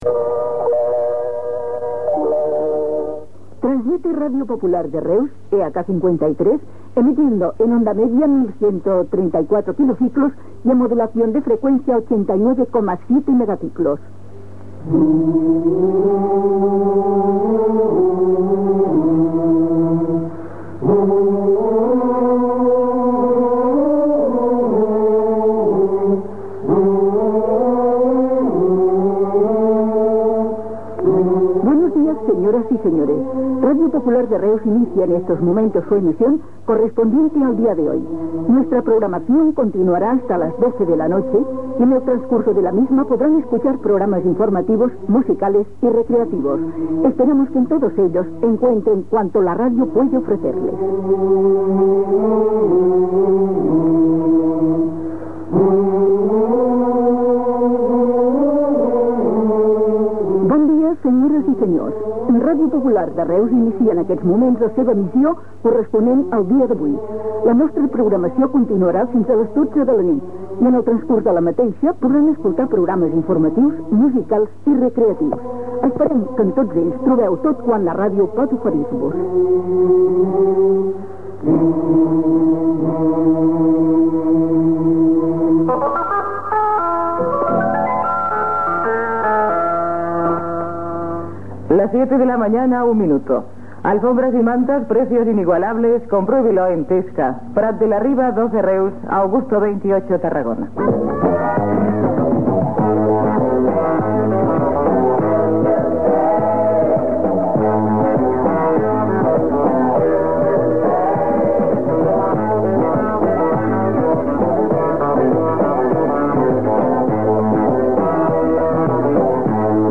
Inici d'emissió i del programa "En marcha".